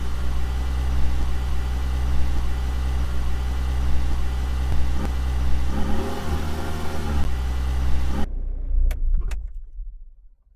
car.ogg